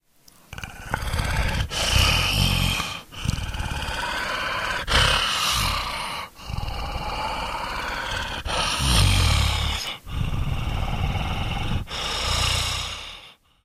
zombie_idle_1.ogg